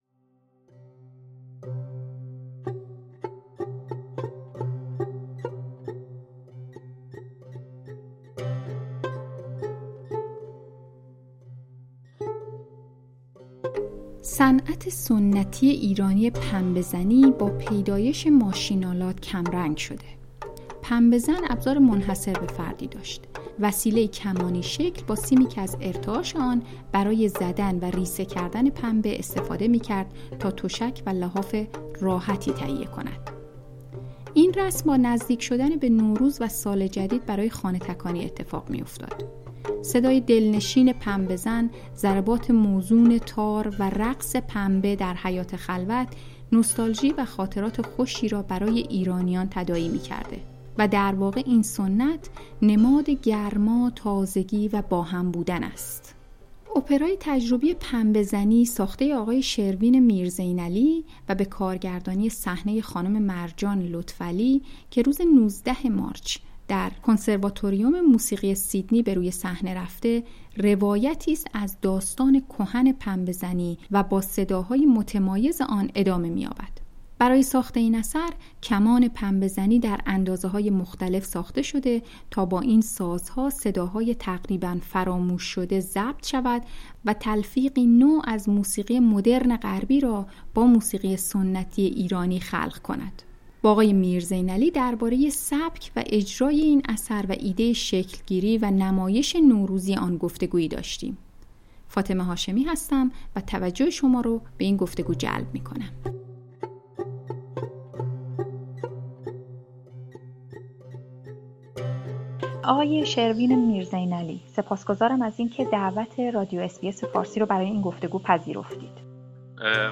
اس بی اس فارسی